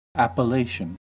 Ääntäminen
Ääntäminen US northern US: IPA : /ˌæ.pəˈleɪ.ʃən/ IPA : /ˌæ.pəˈleɪ.tʃən/ southern US: IPA : /ˌæ.pəˈlæ.tʃən/ Haettu sana löytyi näillä lähdekielillä: englanti Käännöksiä ei löytynyt valitulle kohdekielelle.